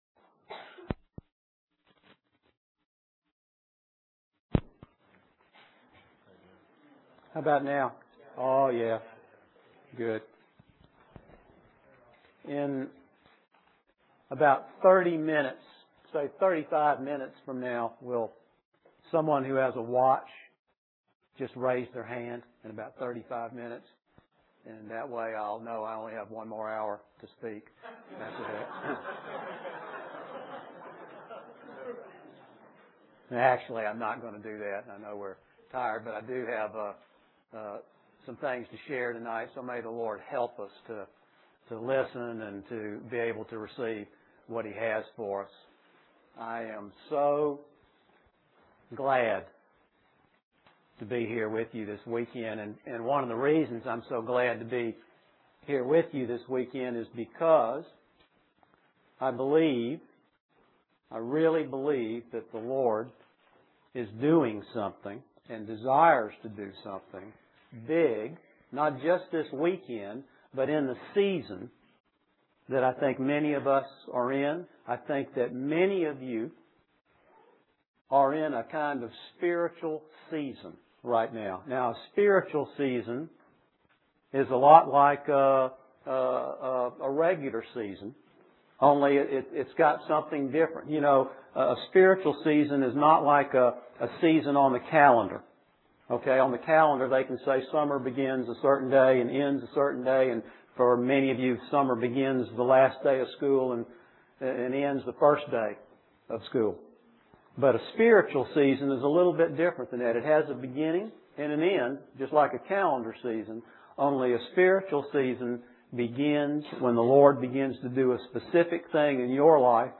A collection of Christ focused messages published by the Christian Testimony Ministry in Richmond, VA.
Winter Youth Conference